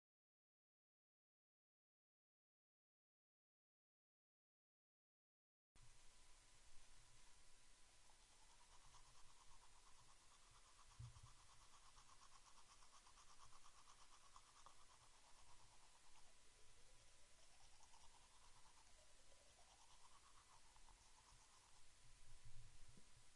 刷牙
描述：这是我用sm57刷牙的记录